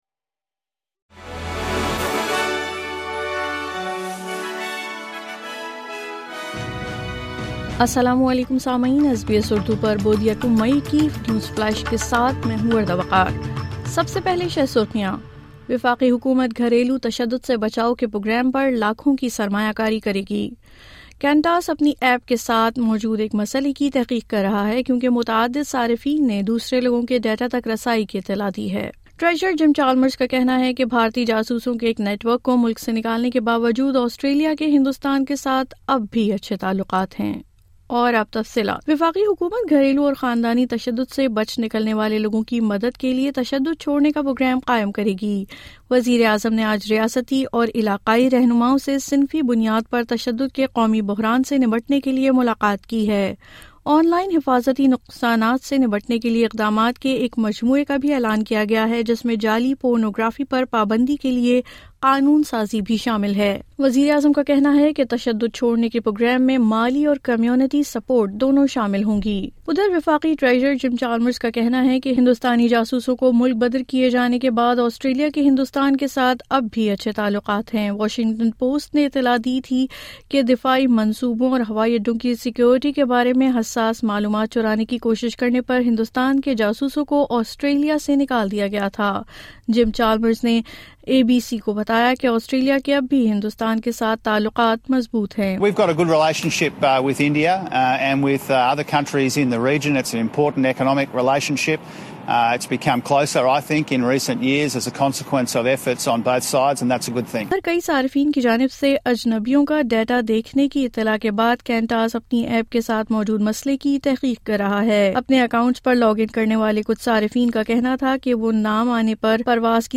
مزید تفصیل کے لئے سنئے اردو خبریں